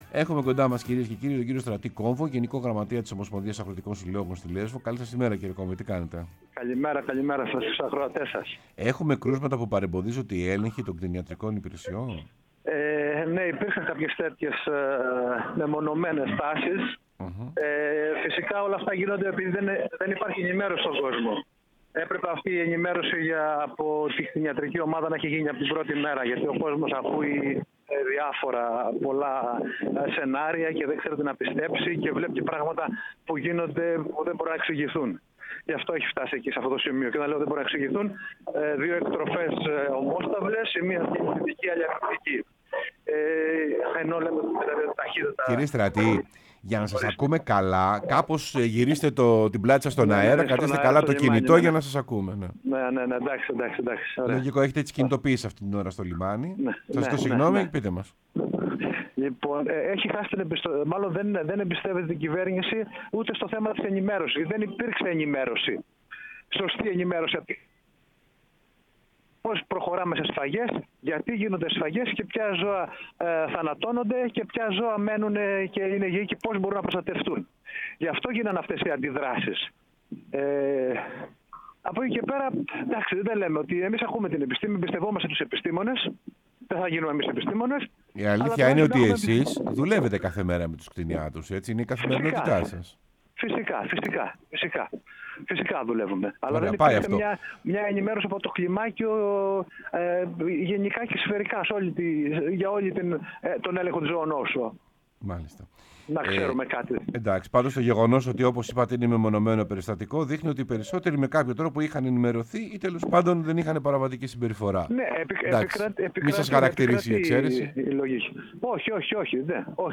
μίλησε στην εκπομπή «Σεμνά και Ταπεινά»